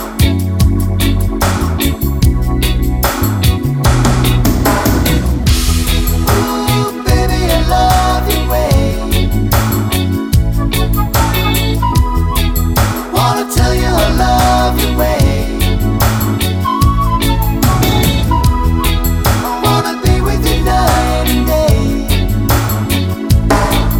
no sax solo or Backing Vocals Reggae 4:20 Buy £1.50